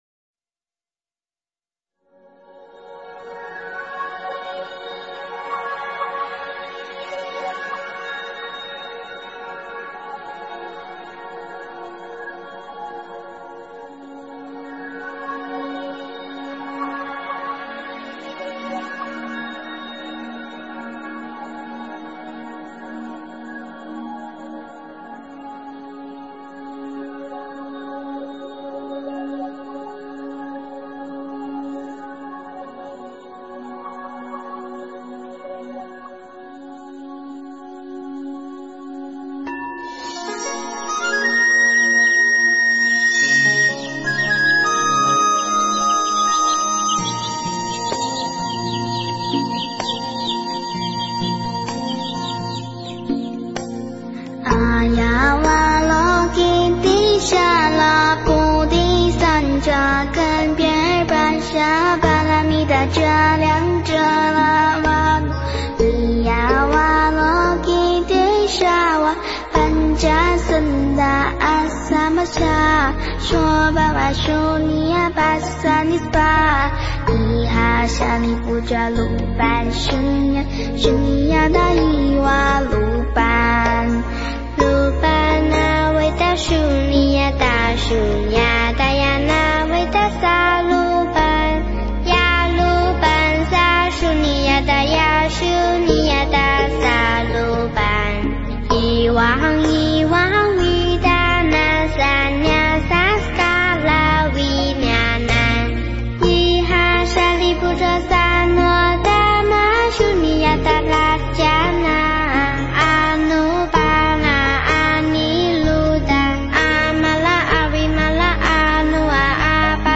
心经.梵唱(童声）
标签: 佛音诵经佛教音乐